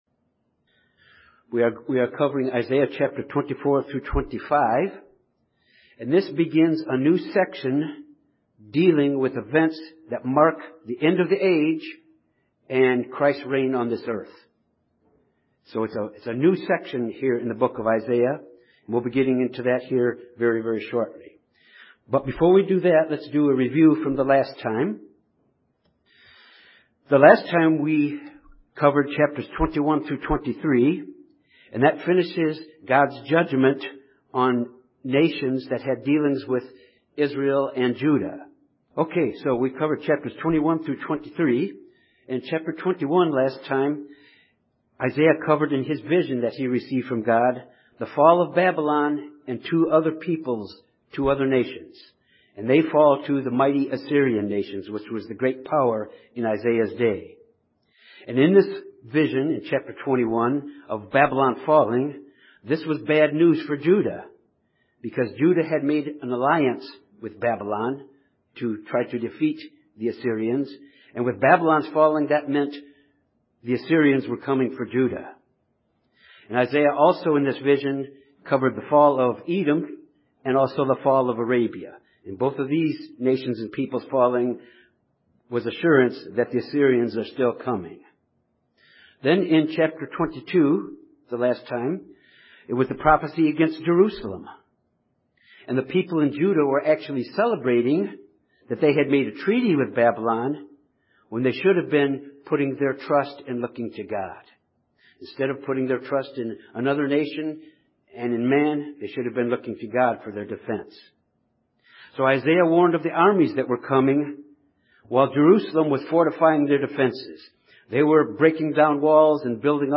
This Bible study covers Isaiah, chapters 24 and 25 which examines a section that deals with God’s judgment at the end of the age and Christ’s reign on the Earth.